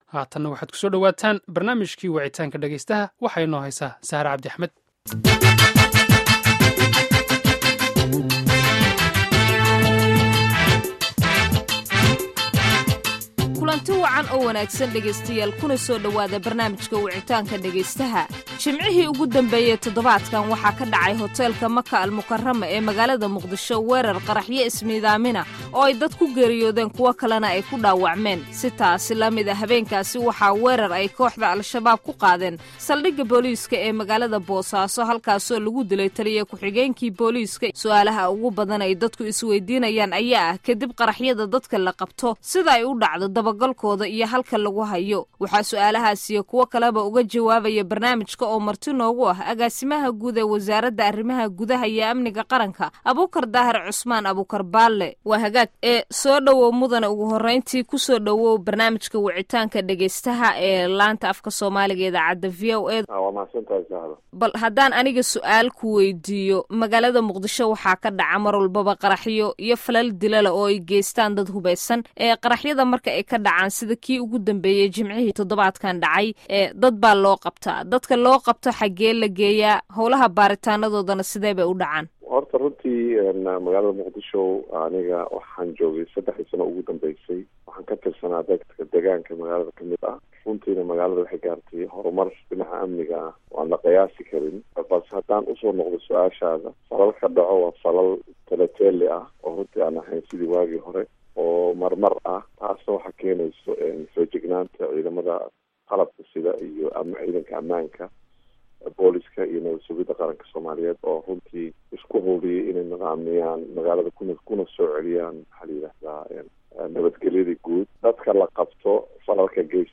Dhageyso Barnaamijka Wicitaanka Dhageystaha